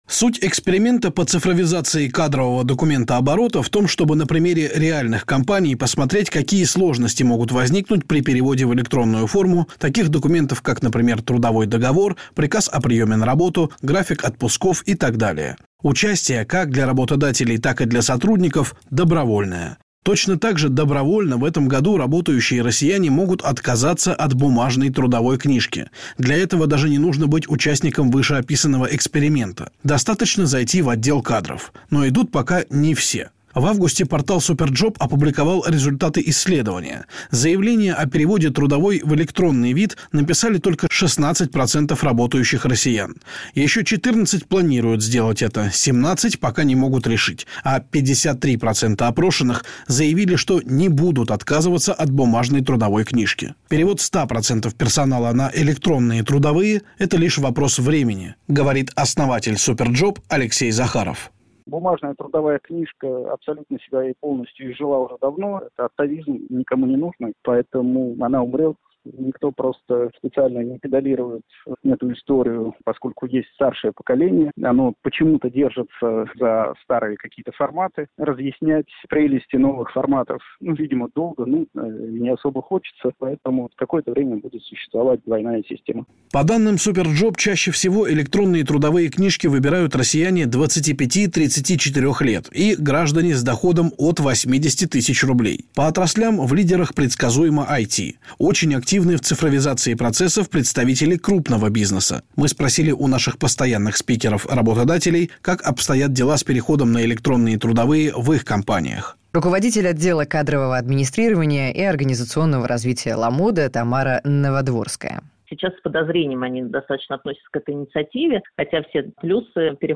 Выступления в СМИ